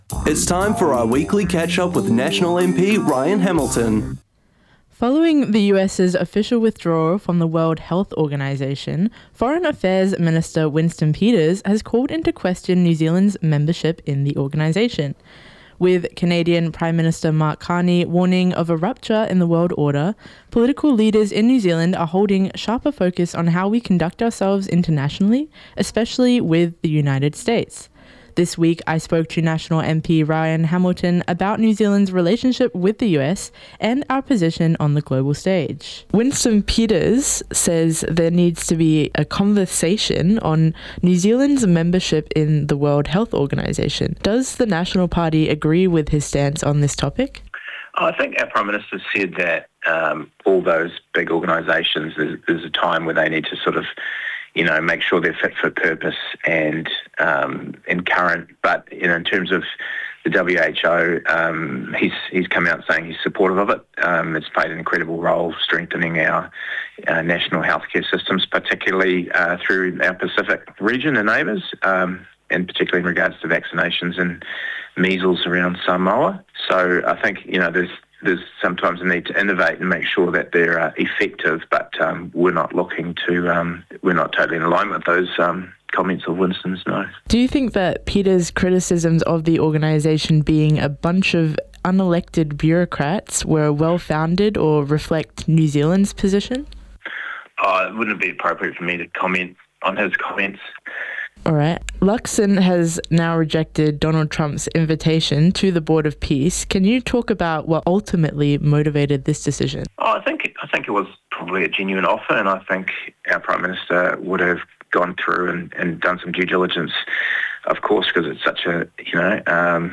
spoke to National MP Ryan Hamilton